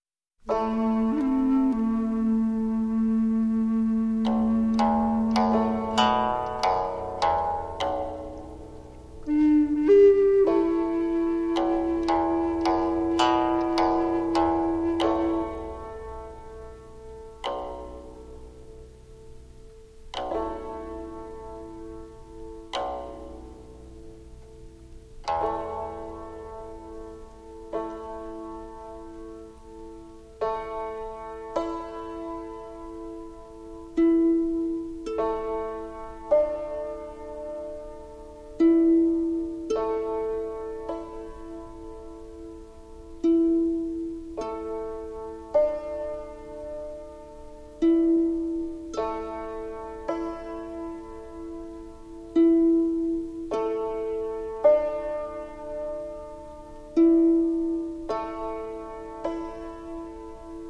CD 1 Original Motion Picture Soundtrack